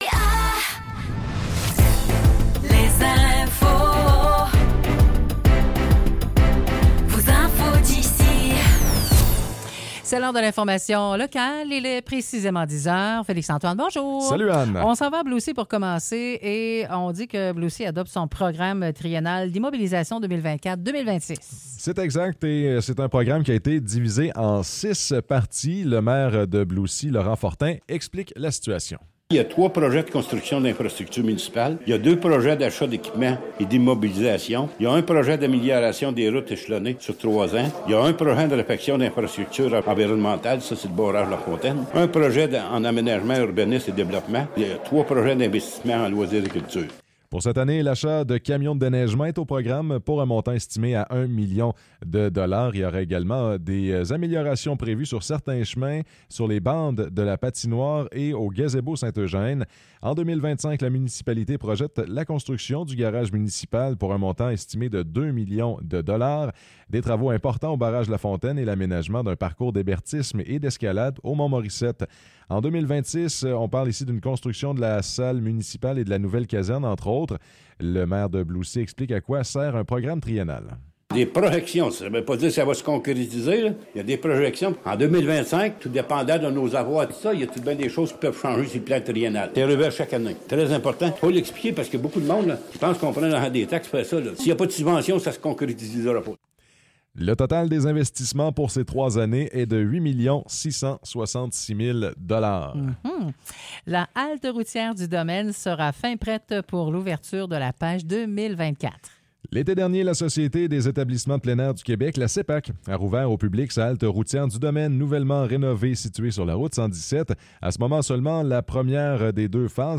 Nouvelles locales - 26 janvier 2024 - 10 h